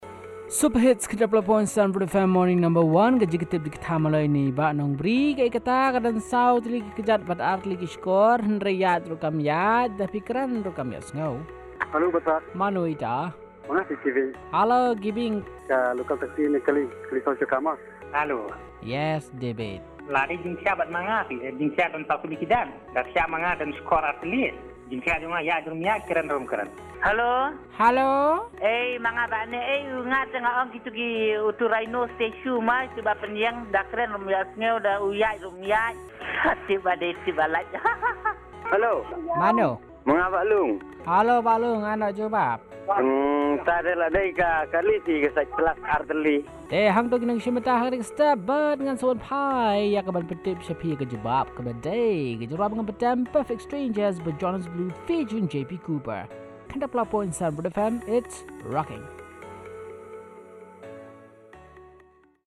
Callers with their answers